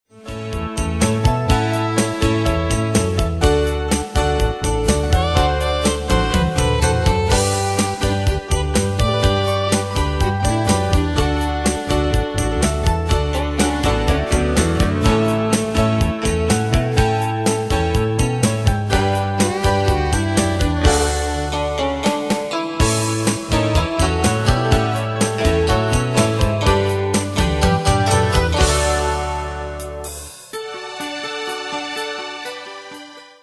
Style: Country Ane/Year: 1975 Tempo: 124 Durée/Time: 3.23
Danse/Dance: Continental Cat Id.
Pro Backing Tracks